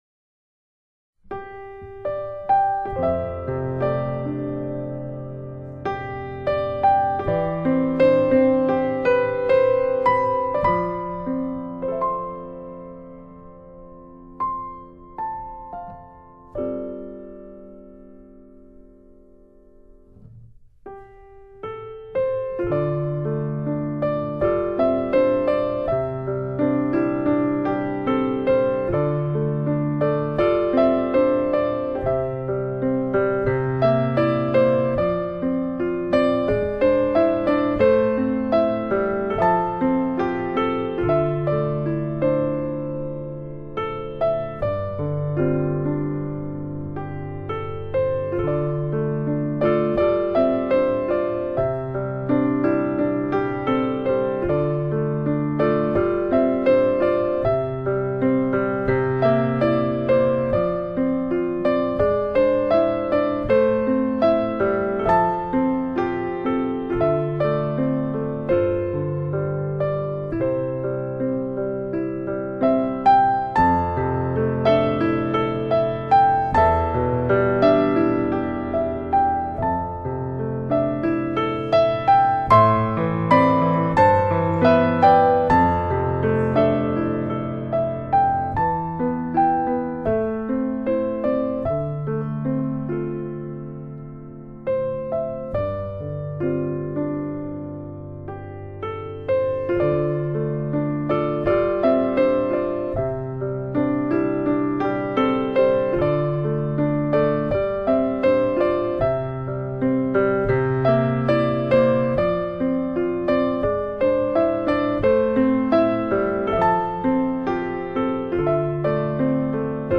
New Age, Piano